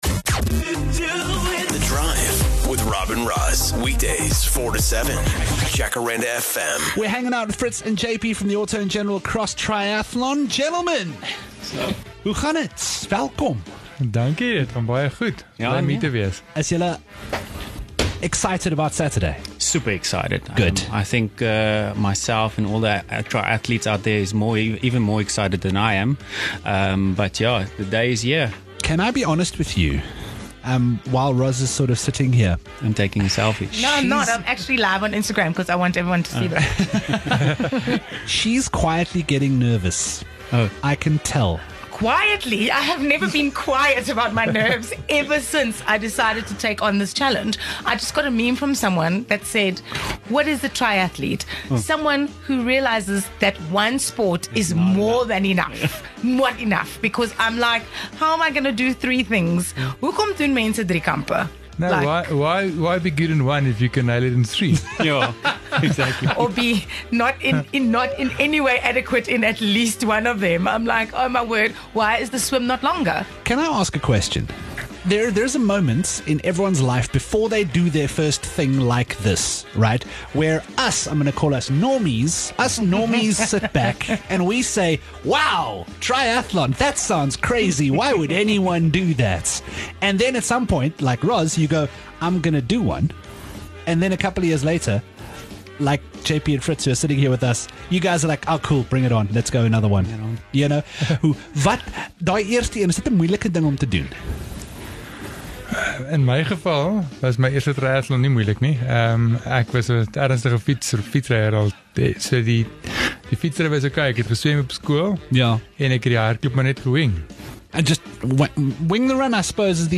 came by the studio to give current triathlon trainers, and any prospective triathlon athletes, a few tips and tricks.